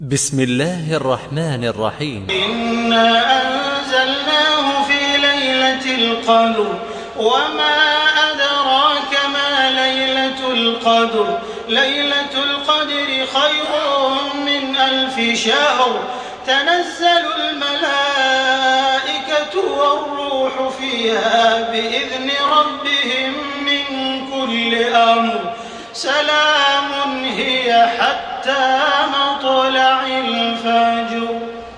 تحميل سورة القدر بصوت تراويح الحرم المكي 1428
مرتل